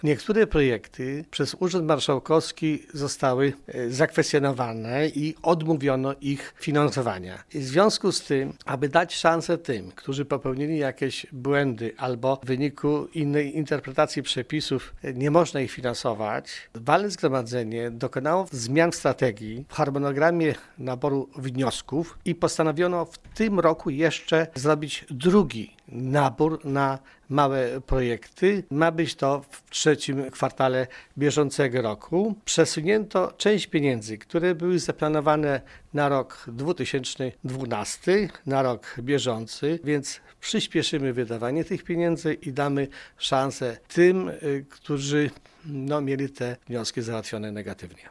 „Wprawdzie ostateczne decyzje w sprawie dofinansowania pomysłów z pierwszego naboru jeszcze nie są znane, ale już wiadomo, że nie wszystkie otrzymają wsparcie” - informuje wiceprezes zarządu LGD, wójt Gminy Łuków Wiktor Osik: